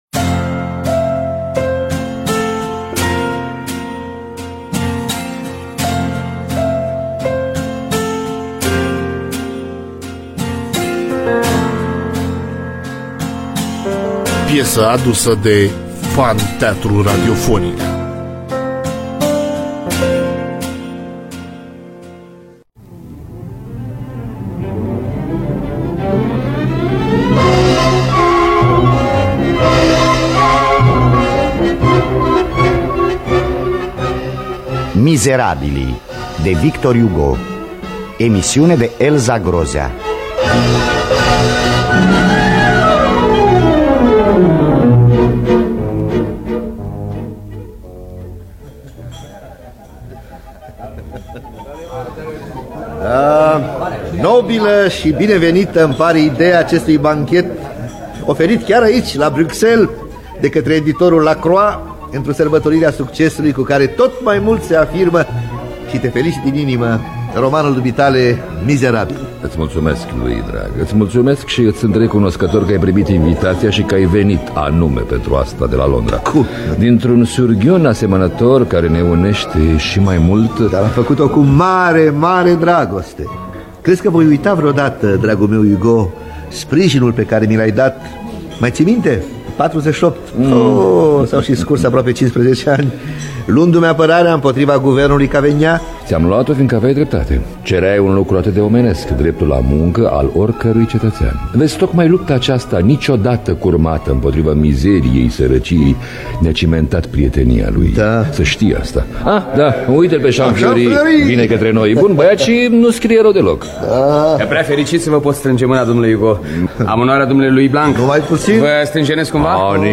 Biografii, Memorii: Victor Hugo – Mizerabilii (1981) – Teatru Radiofonic Online